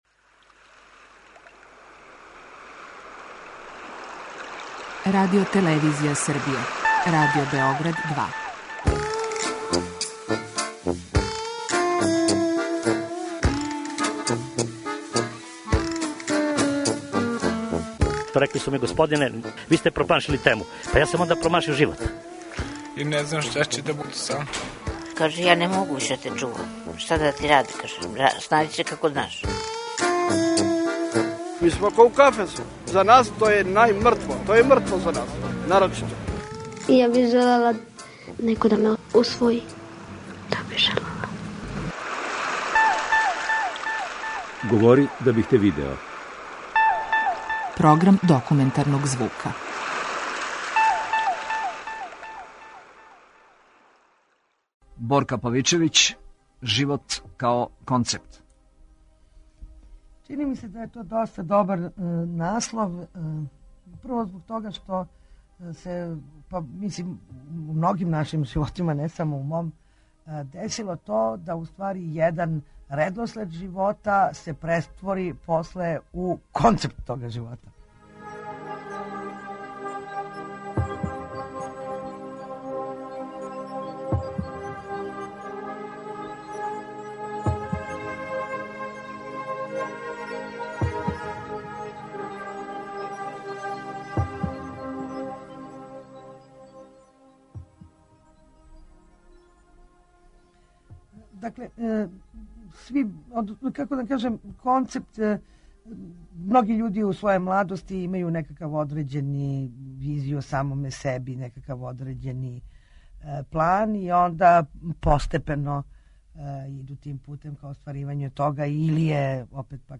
Dokumentarni program